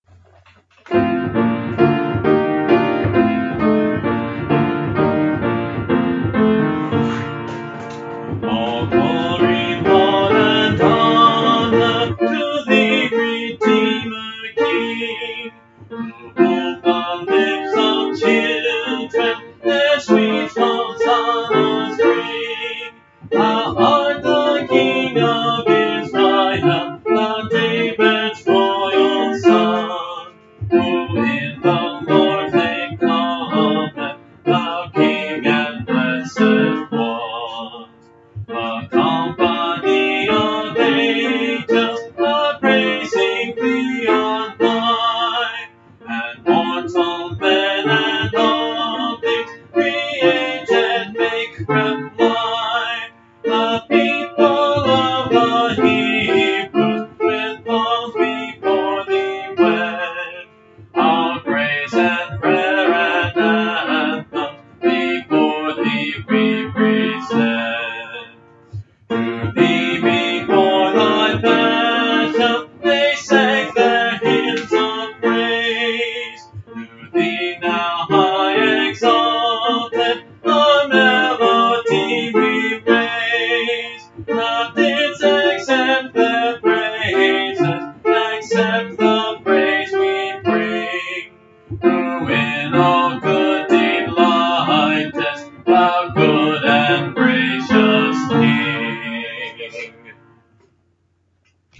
(Part of a series singing through the hymnbook I grew up with: Great Hymns of the Faith)